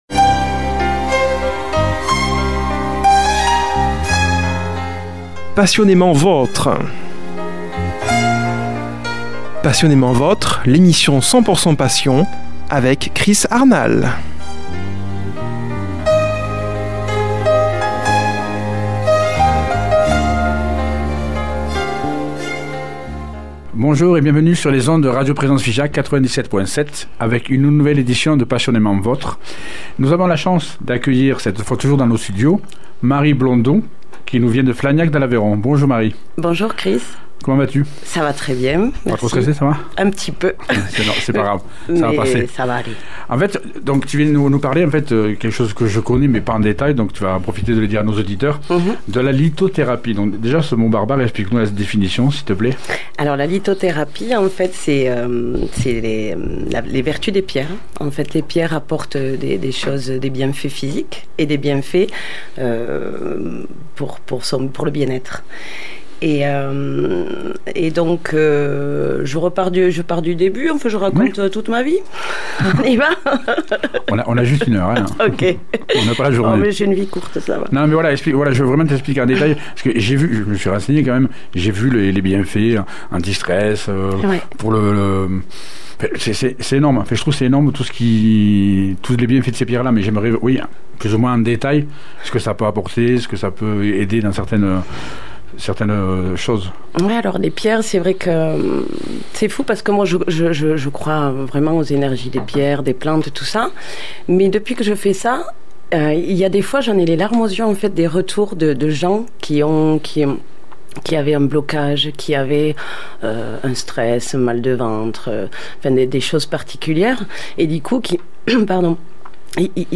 reçoit au studio comme invitée